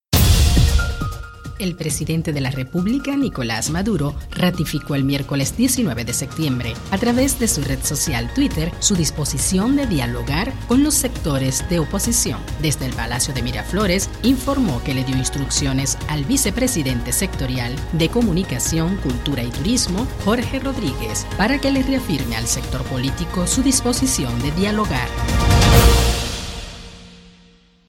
“Sé que la gran mayoría de este país, más allá de las diferencias políticas, cree en el camino de la paz, la democracia, la convivencia, el respeto”, añadió en rueda de prensa desde el Palacio de Miraflores, en Caracas.